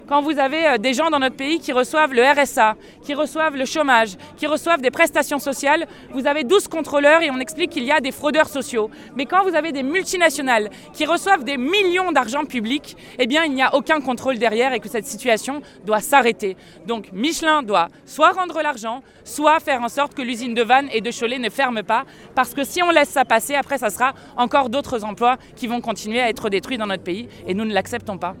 Lors de la mobilisation de vendredi devant l'usine de la Combaude et la manifestation de mercredi au siège de Michelin, les députées ont affiché leur soutien aux salariés menacés de licenciements.